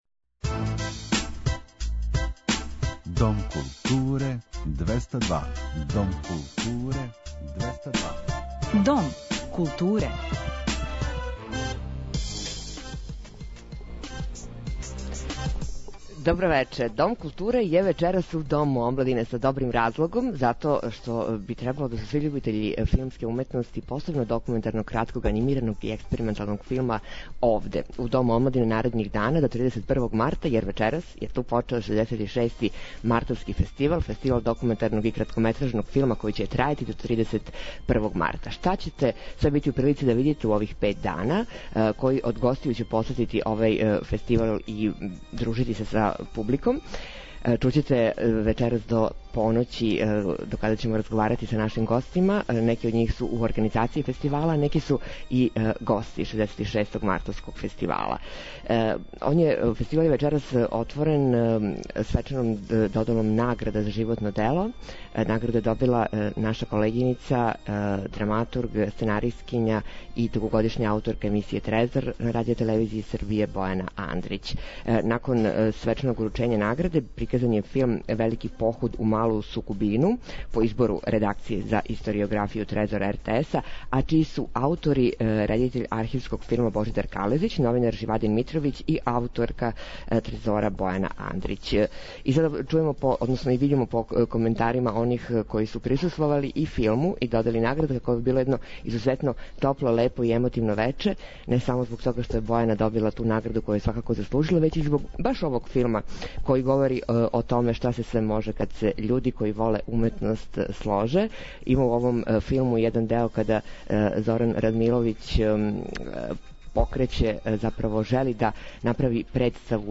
Уживо из Дома омладине, са 66. Мартовског фестивала документарног и краткометражног филма
У нашем фестивалском студију, на једном од најстаријих филмских фестивала у Европи, угостићемо организаторе, селекторе, чланове жирија и филмске ствараоце из целог света.